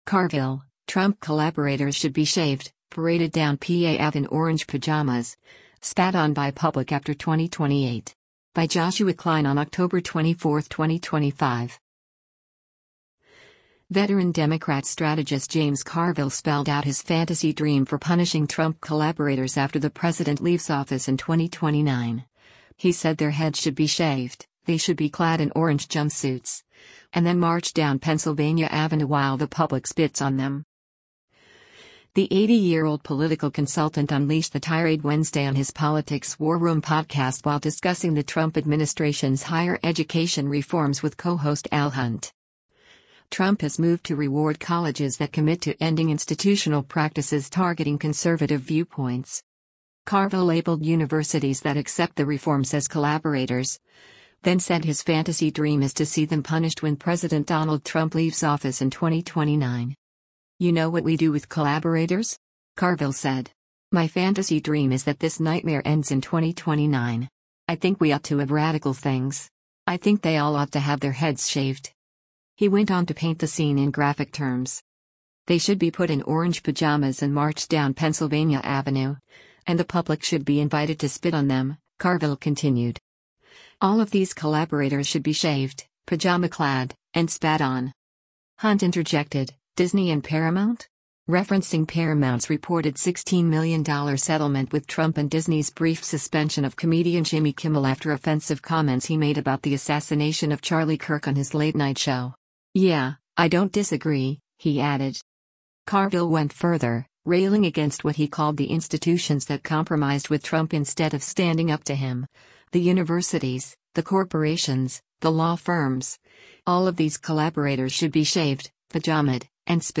The 80-year-old political consultant unleashed the tirade Wednesday on his Politics War Room podcast while discussing the Trump administration’s higher-education reforms with co-host Al Hunt.